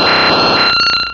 Cri de Ptéra dans Pokémon Rubis et Saphir.